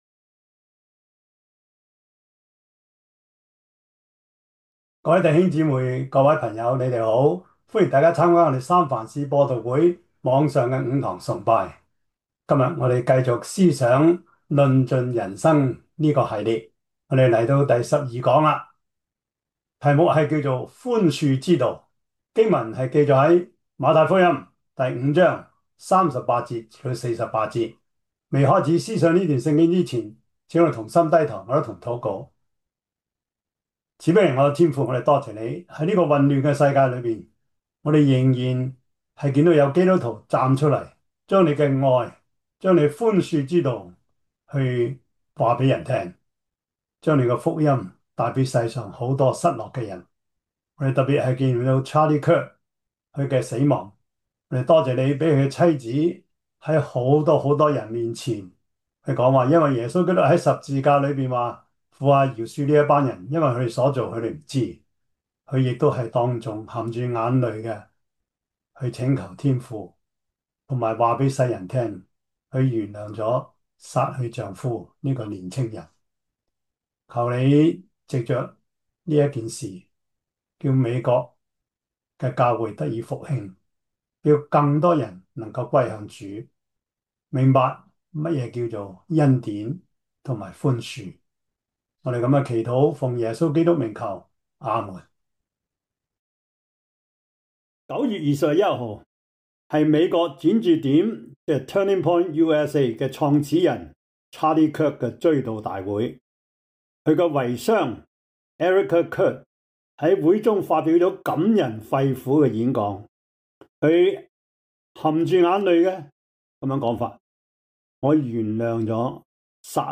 馬太福音 5:38-48 Service Type: 主日崇拜 馬太福音 5:38-48 Chinese Union Version